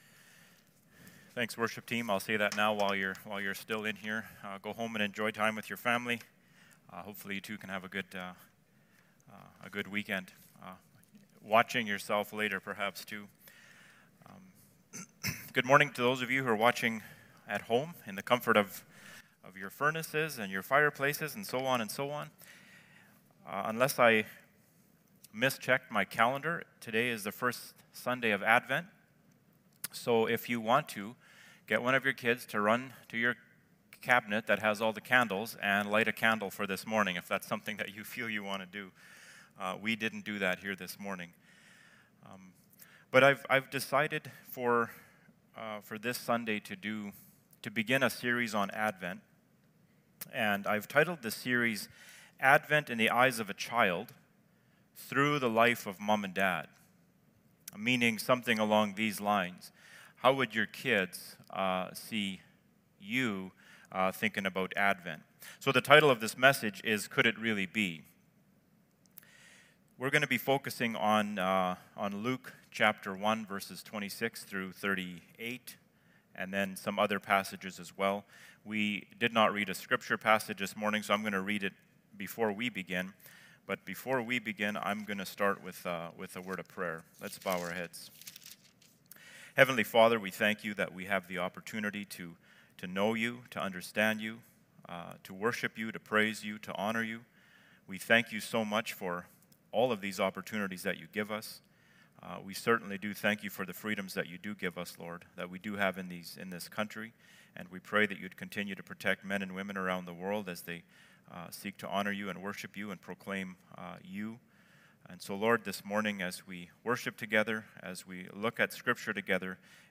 Luke 1:26-33 Service Type: Sunday Morning « The Great Deliverance 2nd Sunday of Advent